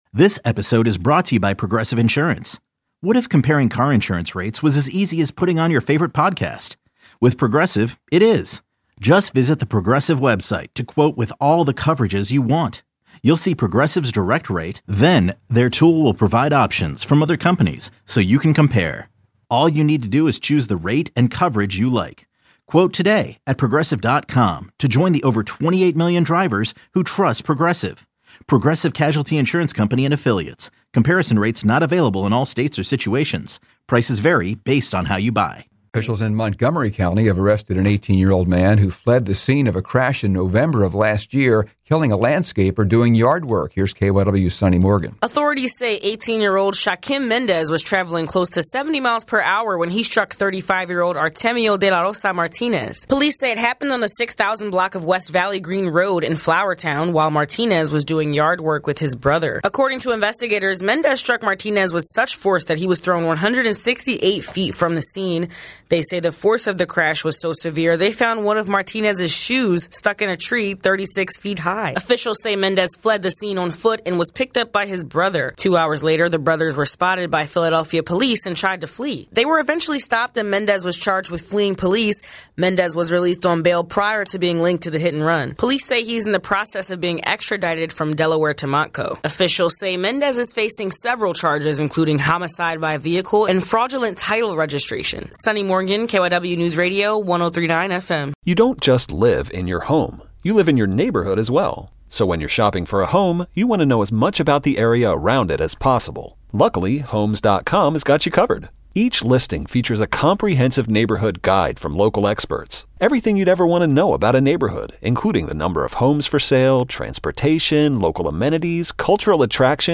The latest news around the Philadelphia region from KYW Newsradio.